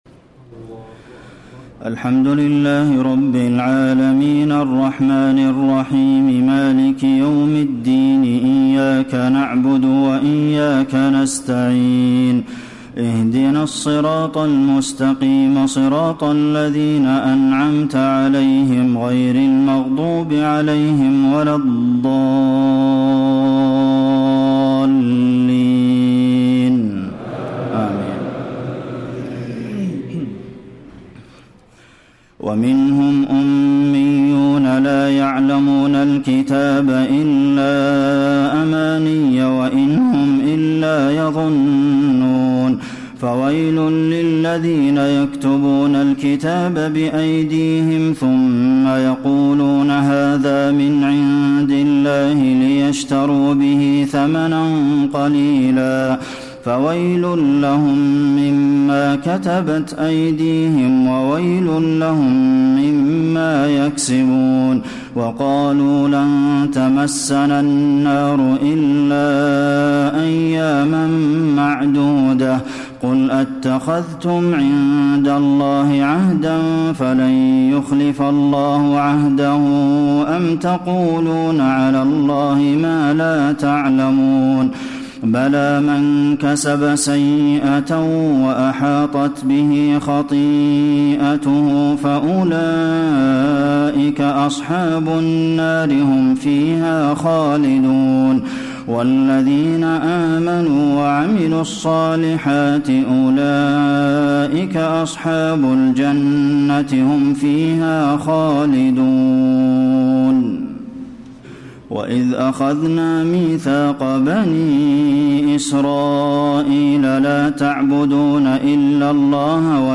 تراويح الليلة الأولى رمضان 1434هـ من سورة البقرة (78-141) Taraweeh 1st night Ramadan 1434 H from Surah Al-Baqara > تراويح الحرم النبوي عام 1434 🕌 > التراويح - تلاوات الحرمين